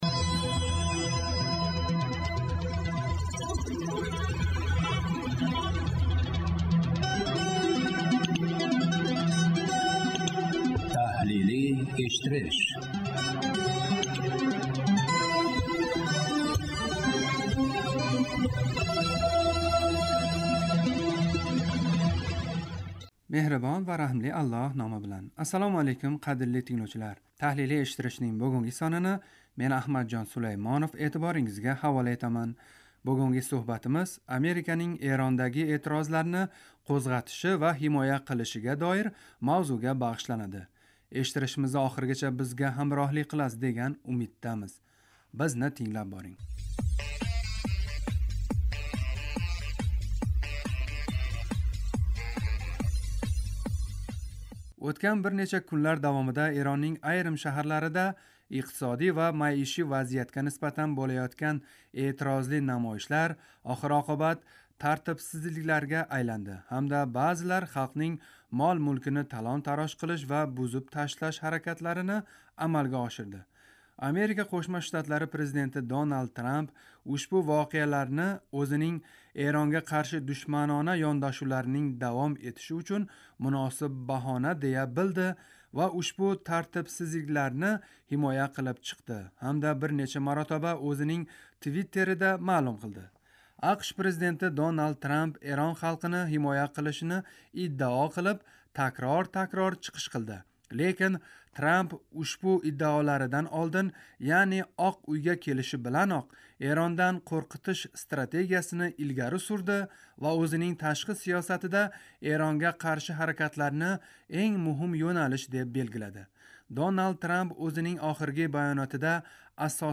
Таҳлилий эшиттиришнинг